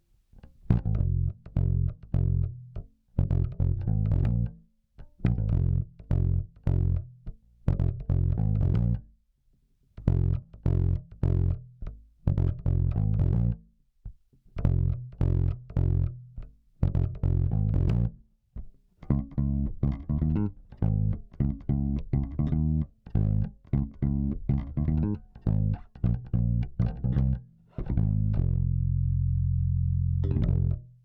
ベースラインでこの曲なーんだ
このベースラインは聴いたことがある！